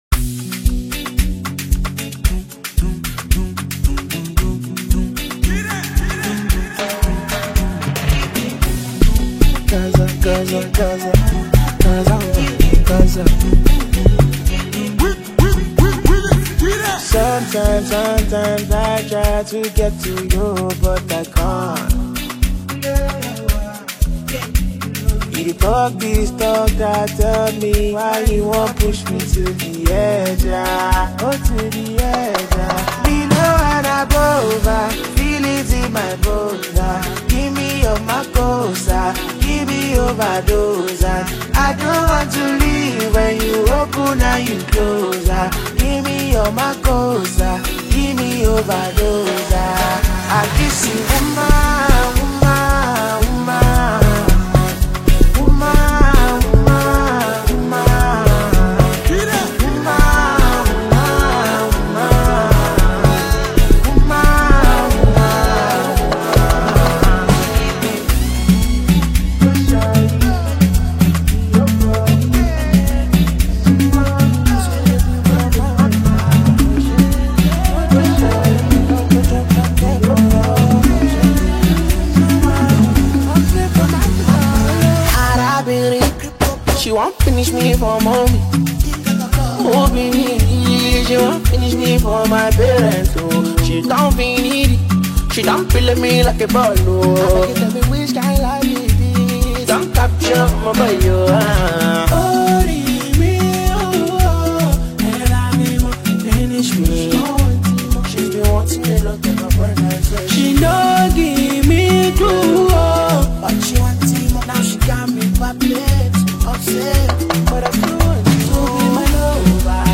Nigerian Afropop singer and songwriter
did a magnificent job with his vocals and deliveries.
free-flowing lyricism and radiant riddims